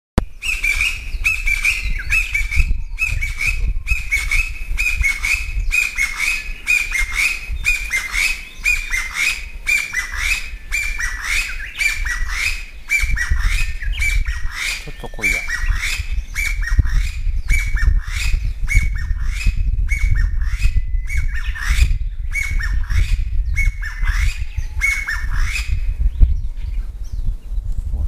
コジュケイの鳴き声
かなりにぎやかなさえずりです。「ちょとこい、ちょっとこい」と聞きなしするそうです・
kojyukei.mp3